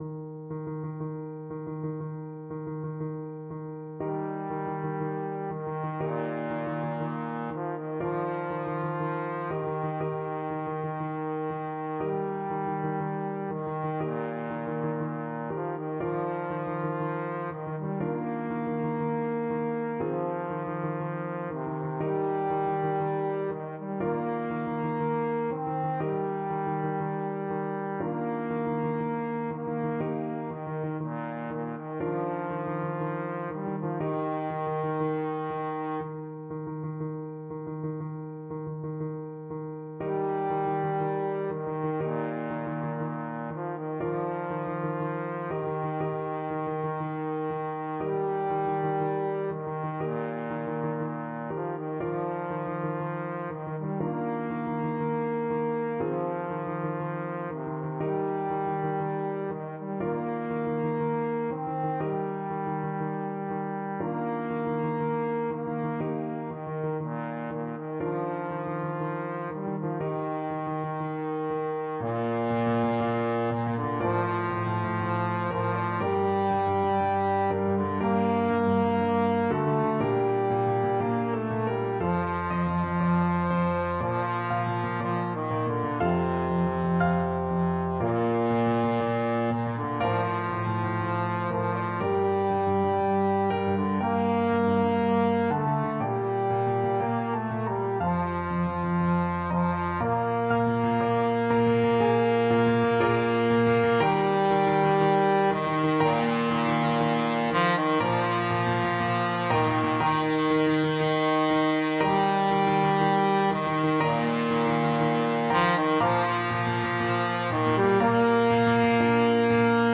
Nobilmente = c. 60
4/4 (View more 4/4 Music)
Bb3-C5
Arrangement for Trombone and Piano